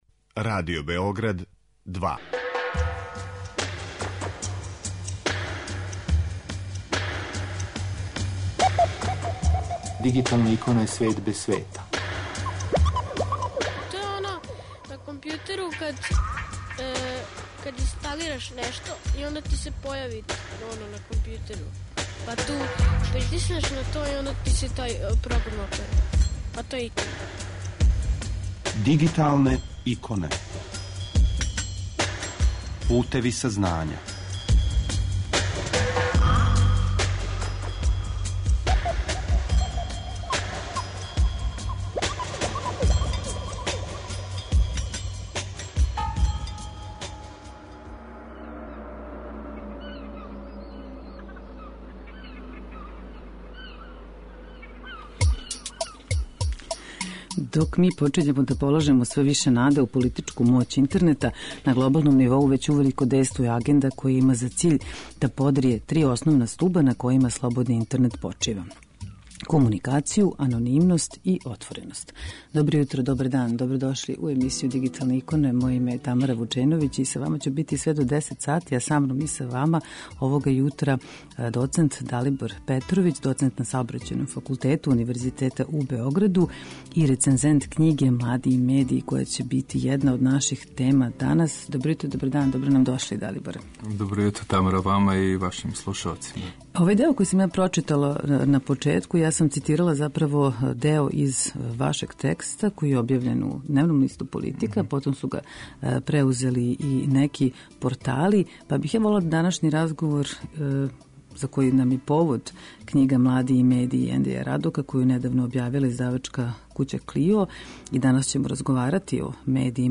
Са нама уживо